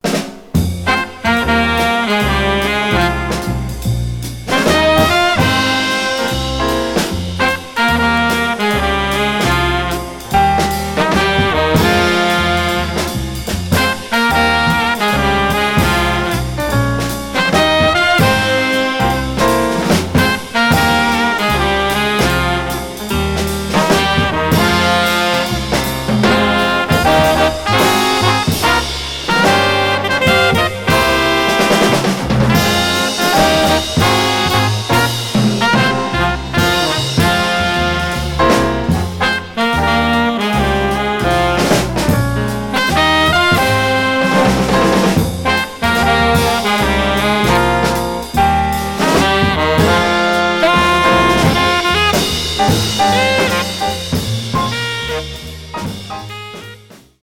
Swing (medium)